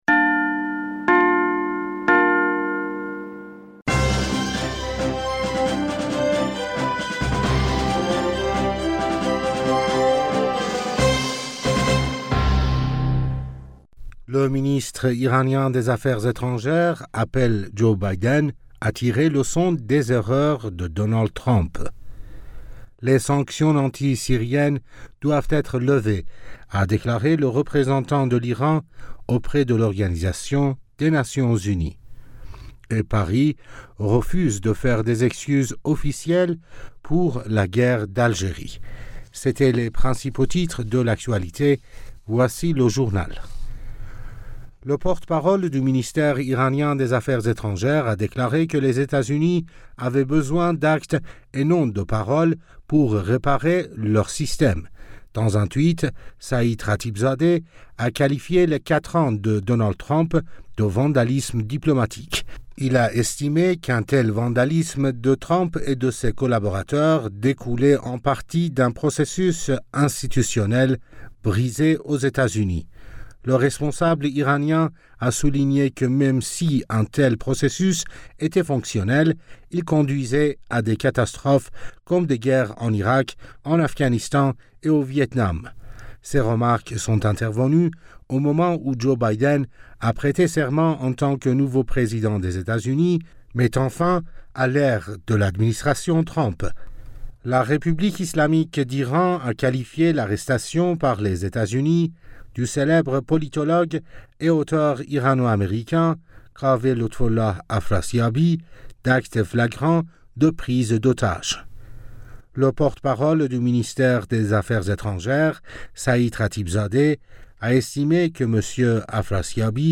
Bulletin d'informationd u 21 Janvier 2021